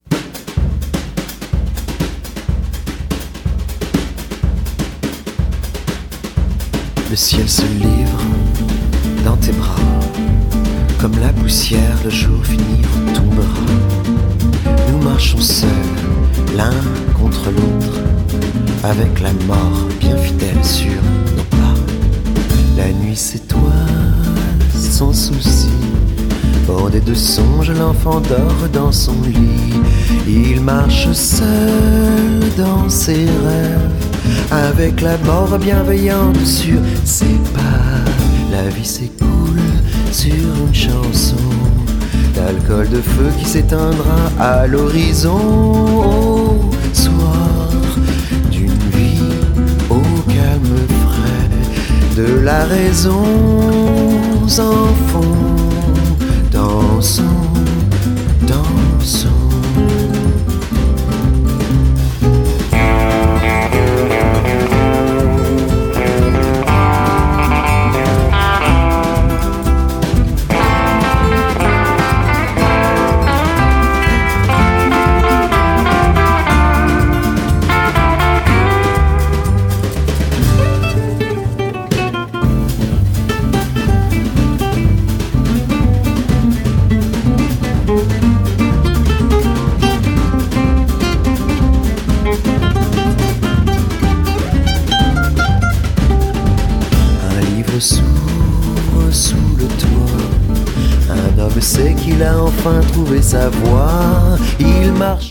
絶品！乾いた官能！アリゾナ経由のフレンチ・アヴァン・ポップ！砂漠のボサノヴァ・レコード！